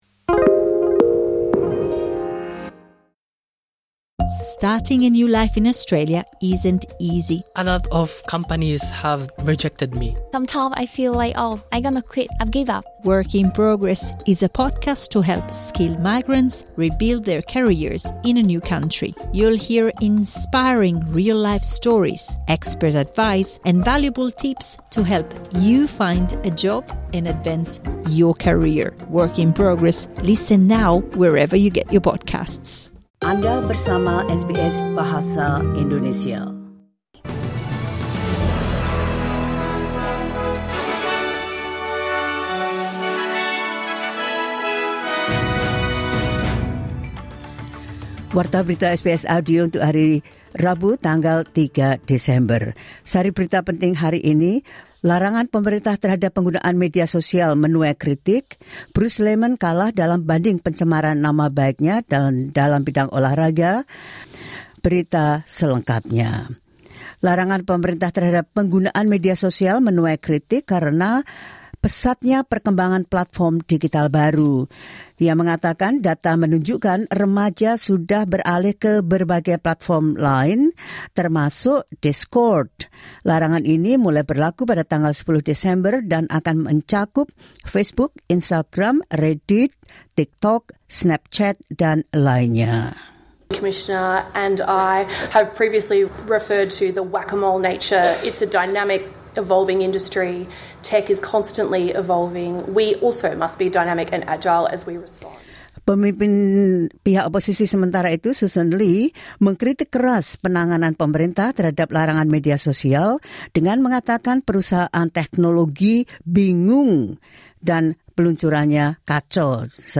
The latest news SBS Audio Indonesian Program – 03 December 2025.